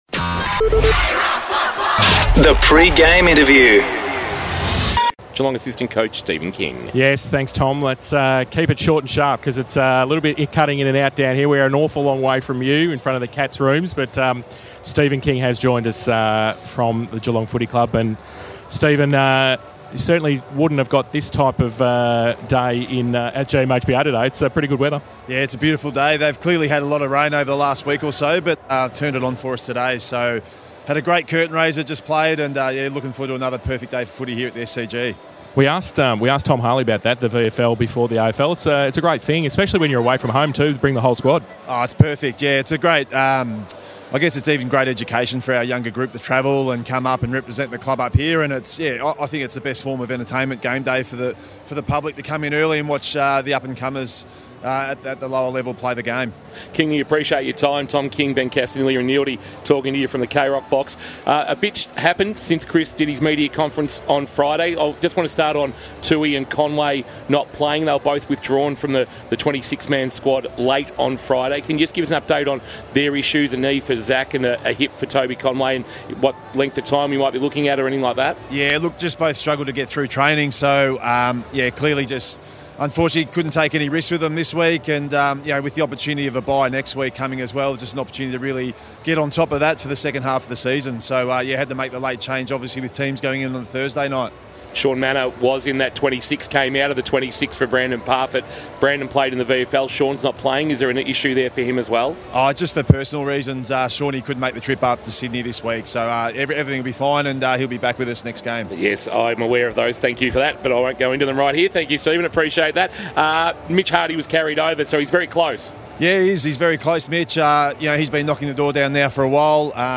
2024 - AFL - Round 13 - Sydney vs. Geelong: Pre-match interview